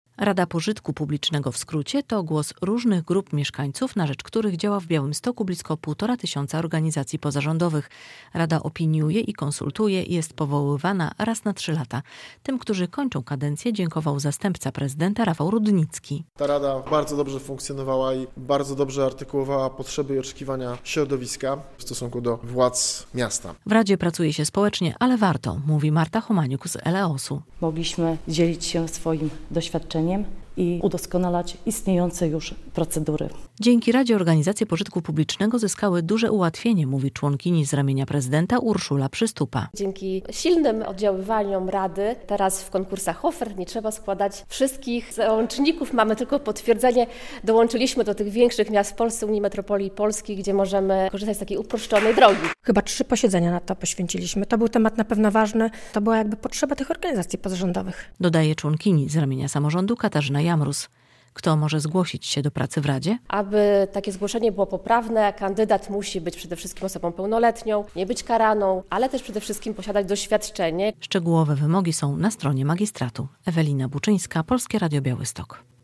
Białostocka Rada Działalności Pożytku Publicznego - relacja